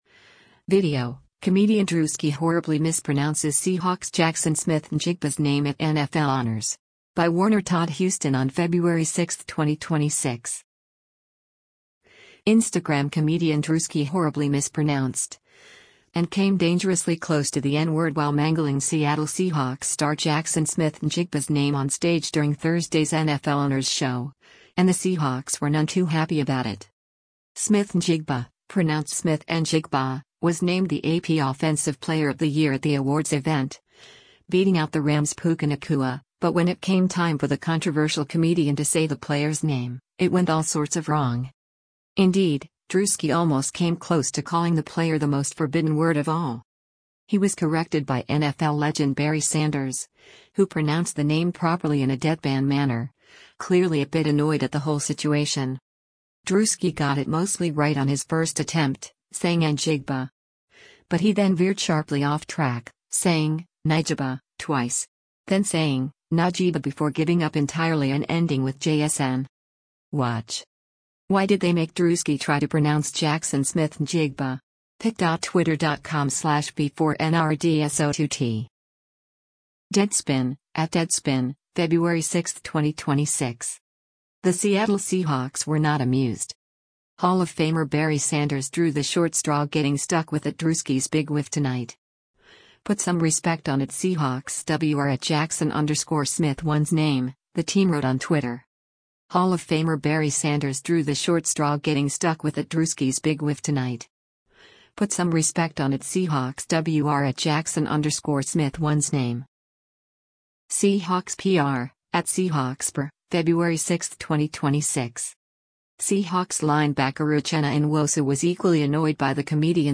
Instagram comedian Druski horribly mispronounced—and came dangerously close to the “N-Word” —while mangling Seattle Seahawks star Jaxson Smith-Njigba’s name on stage during Thursday’s NFL Honors show, and the Seahawks were none too happy about it.
Smith-Njigba (pronounced Smith-N-jig-ba) was named the AP Offensive Player of the Year at the awards event, beating out the Rams’ Puka Nacua, but when it came time for the controversial comedian to say the player’s name, it went all sorts of wrong.
He was corrected by NFL legend Barry Sanders, who pronounced the name properly in a deadpan manner, clearly a bit annoyed at the whole situation.
Druski got it mostly right on his first attempt, saying “N-jigba.” But he then veered sharply off track, saying, “Nigaba” — twice.